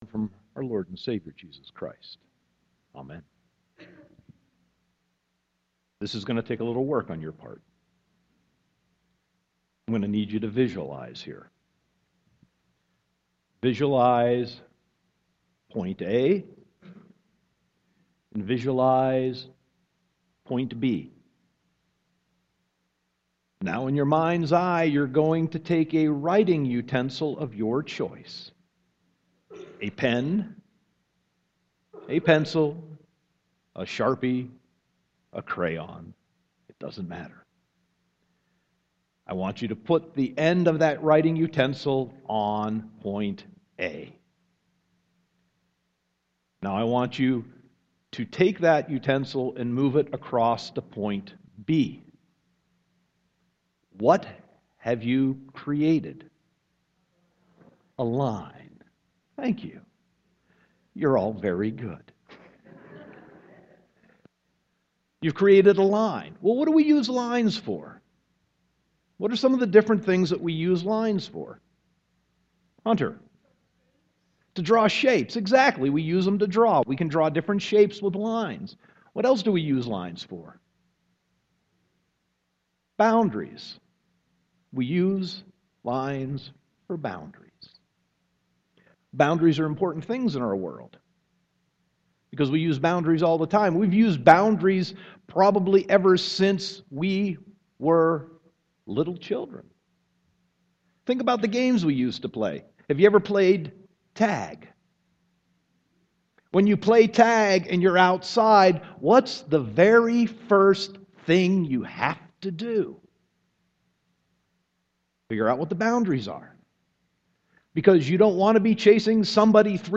Sermon 5.10.2015